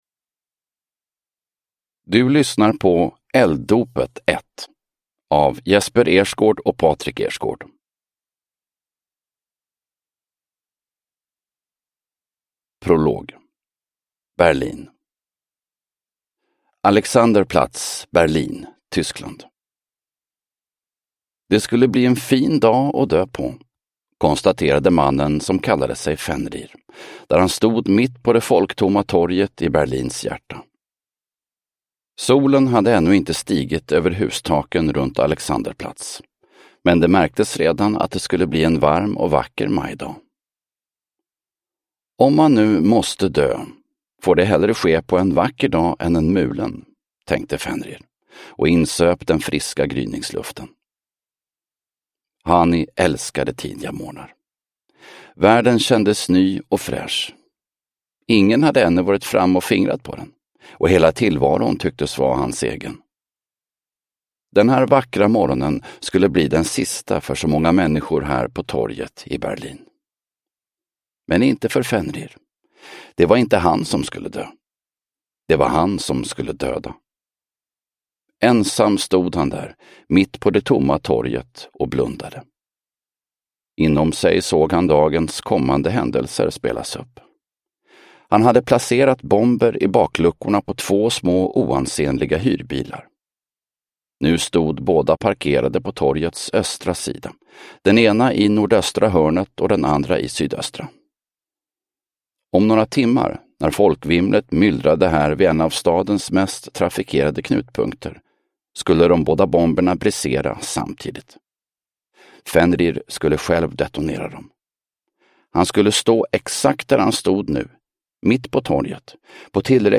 Elddopet – Ljudbok – Laddas ner